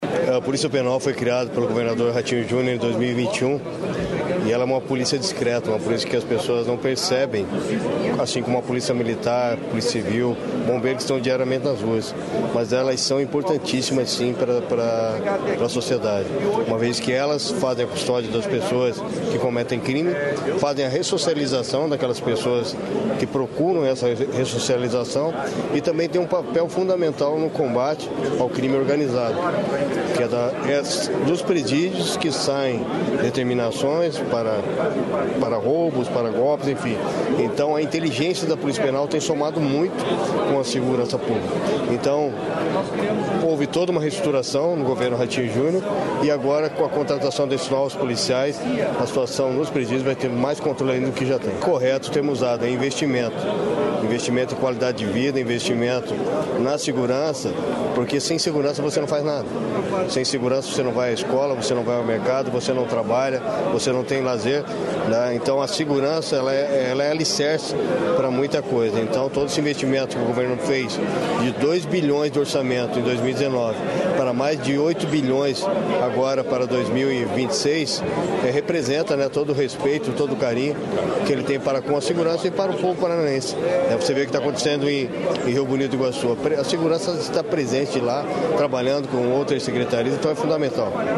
Sonora do secretário da Segurança Pública, Hudson Leôncio Teixeira, sobre a contratação de policiais penais